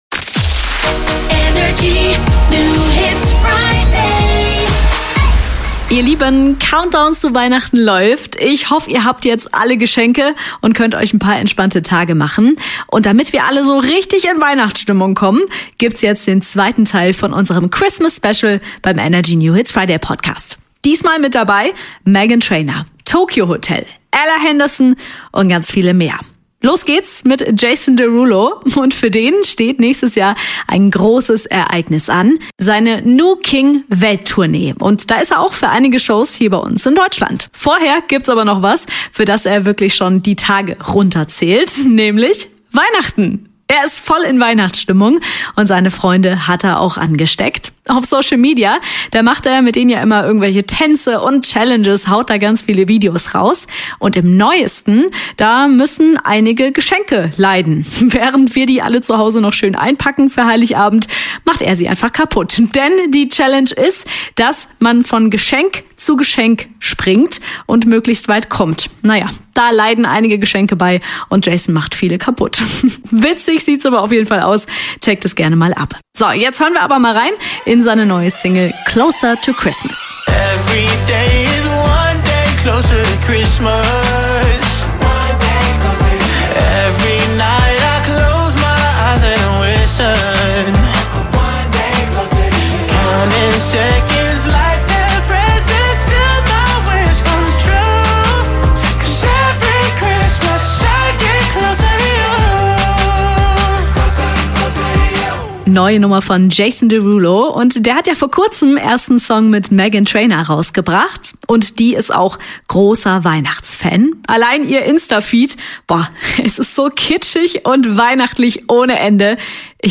Heute wird's wieder weihnachtlich! Höre die neuen Christmas-Songs